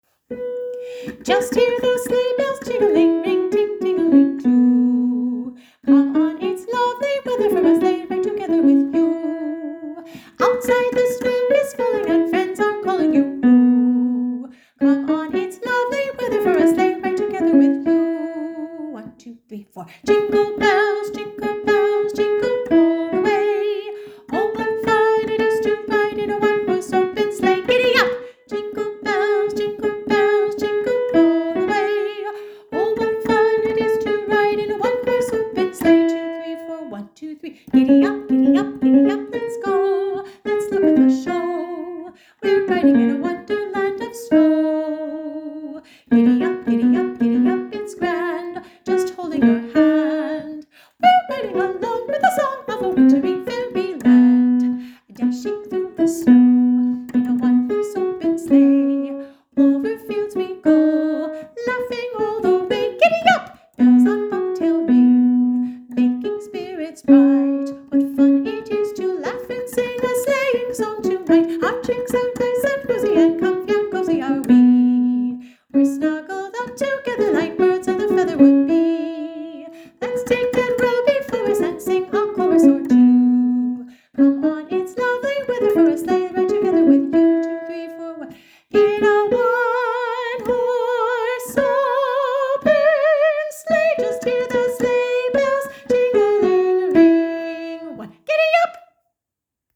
sleigh-bells_jingle-bells-mash-upDownload Harmony sing along track Sleigh Ride Jingle mashup